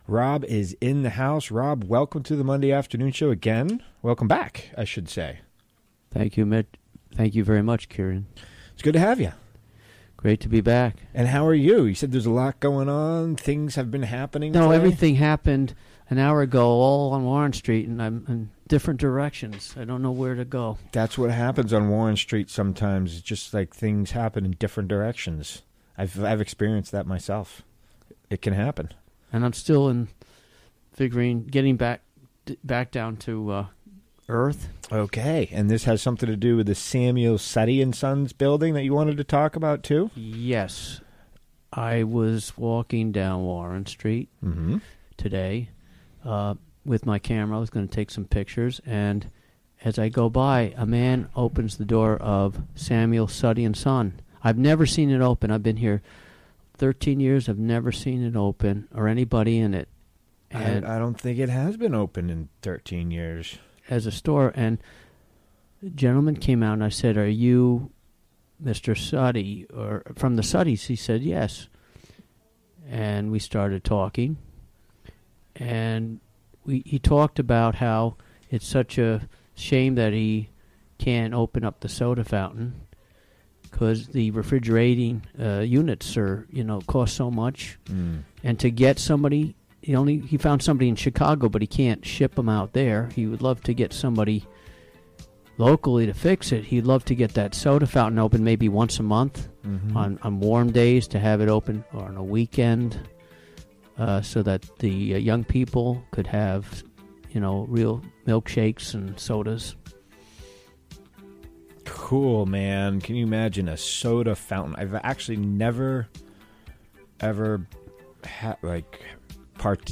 Recorded during the WGXC Afternoon Show Monday, January 22, 2018.